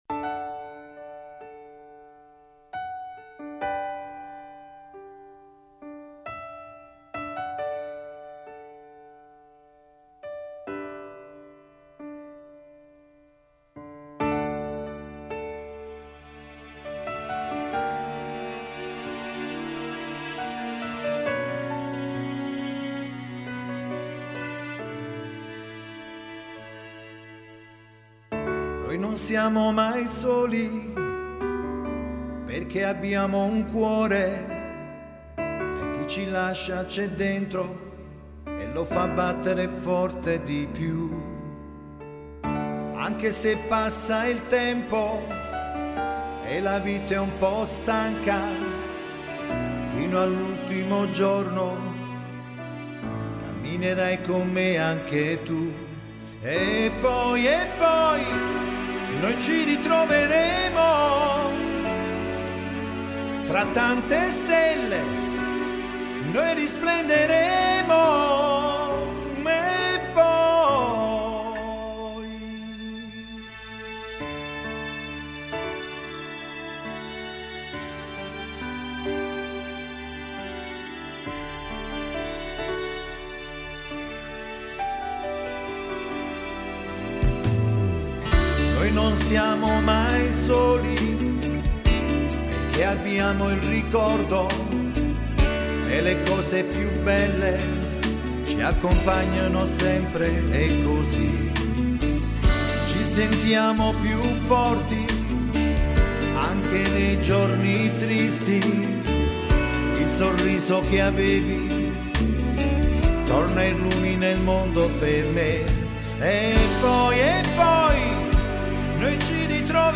Guitar
Bass
Drum & Percussion
Sax